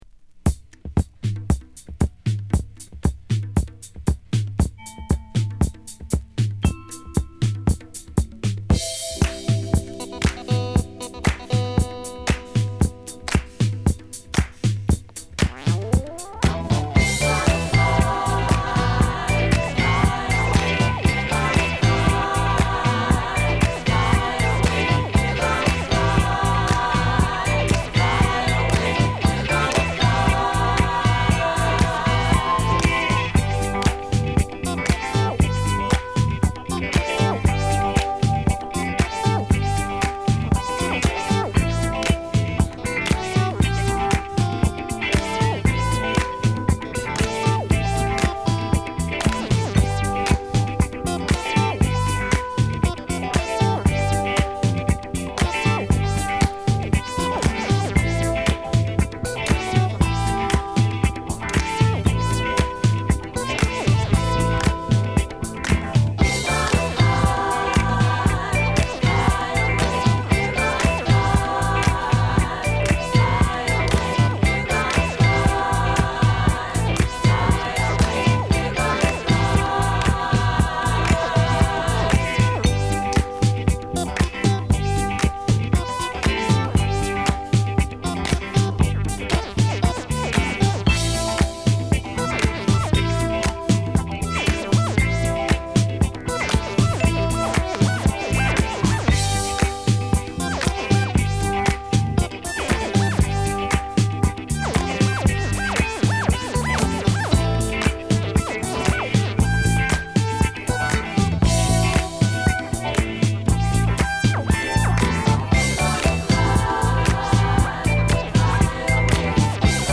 12インチならではのファットかつクリアーな鳴りでフロア映え抜群です！